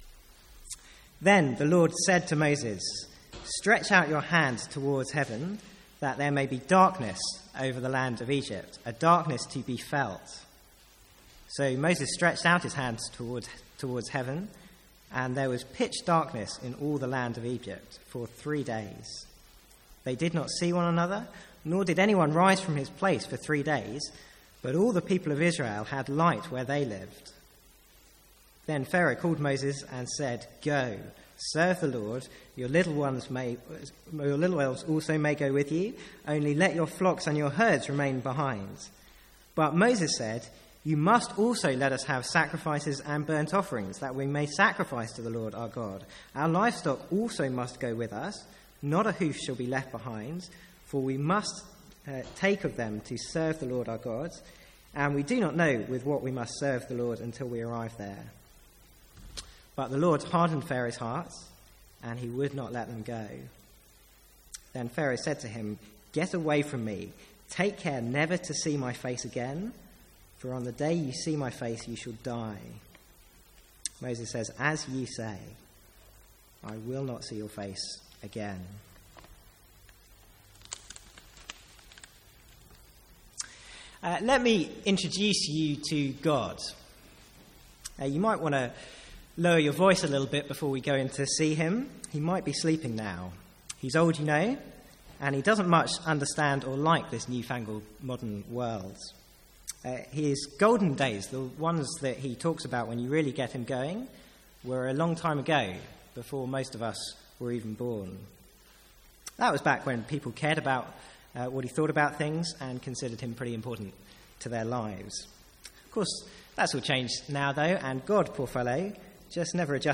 Sermons | St Andrews Free Church
From the Sunday evening series in Exodus.